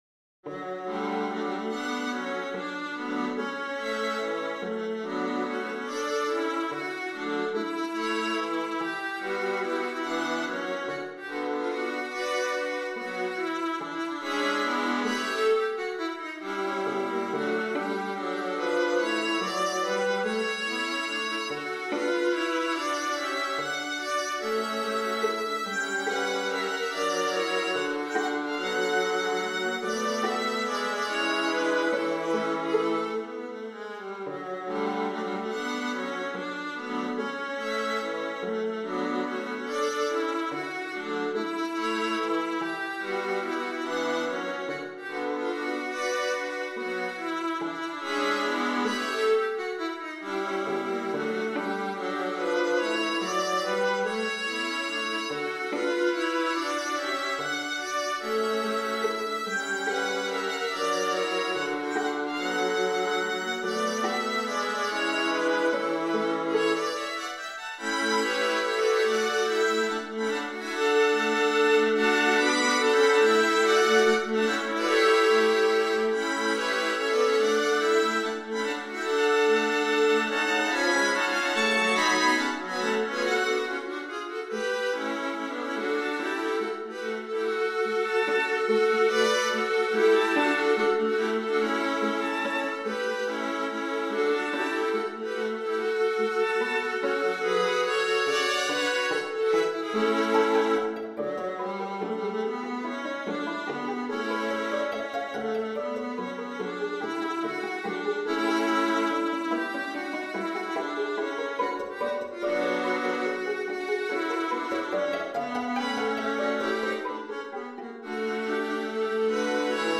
viola quartets